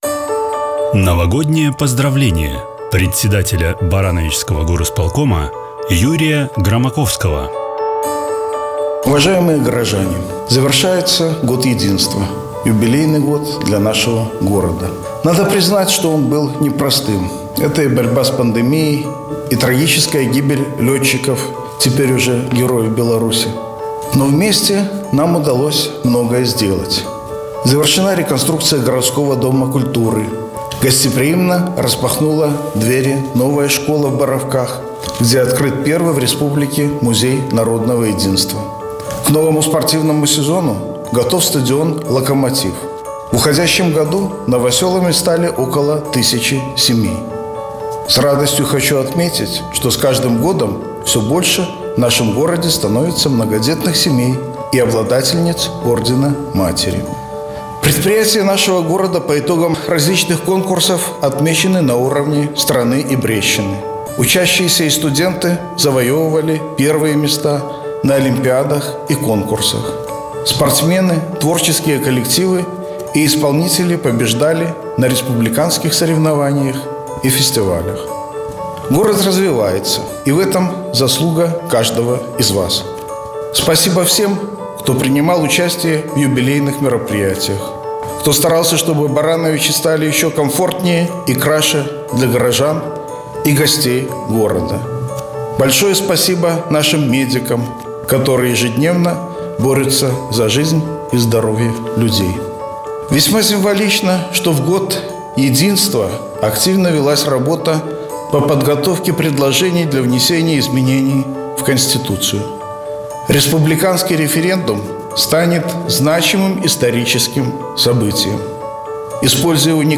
Поздравление с Новым годом председателя Барановичского городского исполнительного комитета Юрия Громаковского.